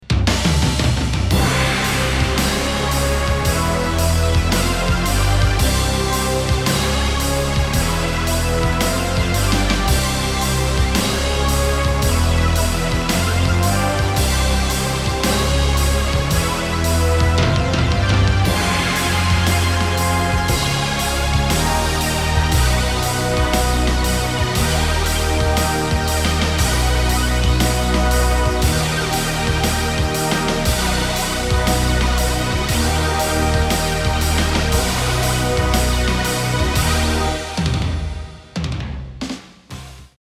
CLASSIC SONG COVERS
all programming and Keyboards.
Amen drum loop.
Drum samples.